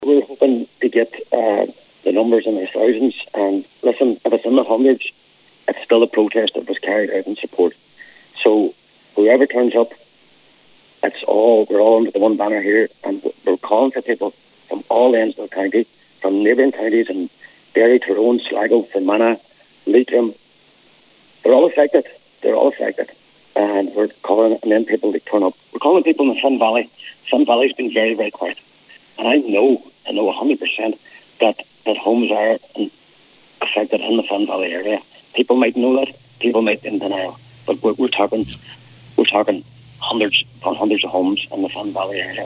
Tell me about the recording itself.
on today’s Nine til Noon Show he appealed to people from near and far to show their support: